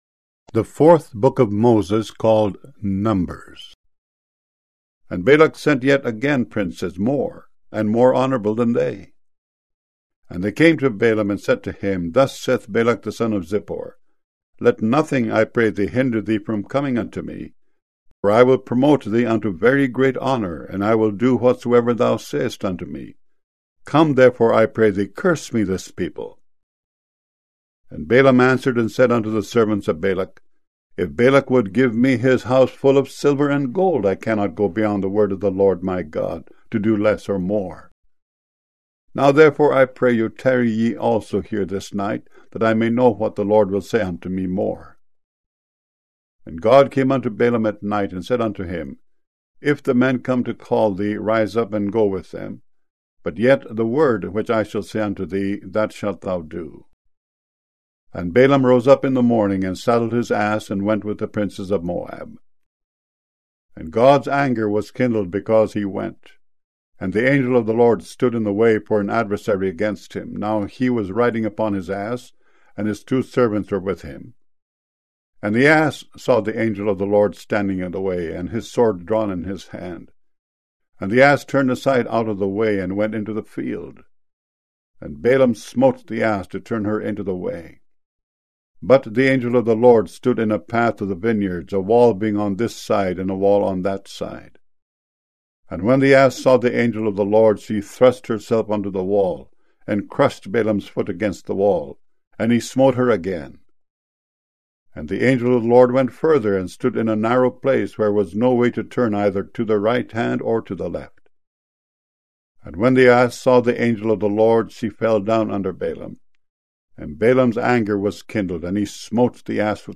Daily Devotional Audio Readings MP3_03_March